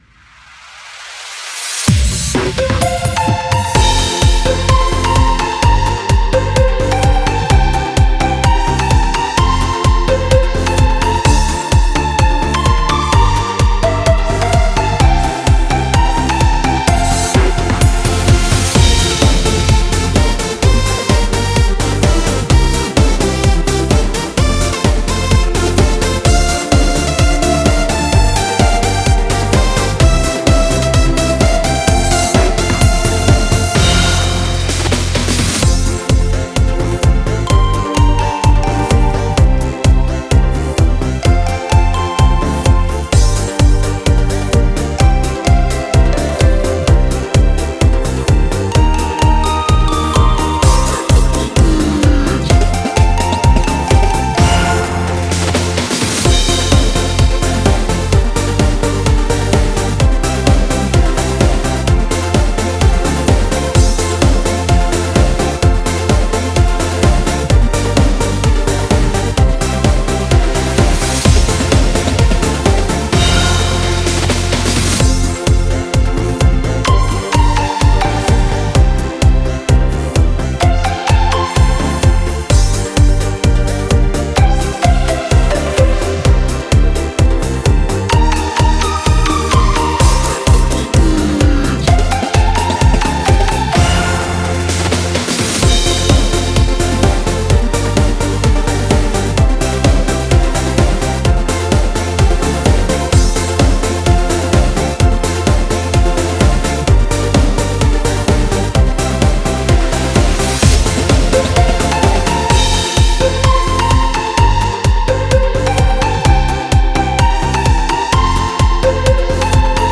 Beat: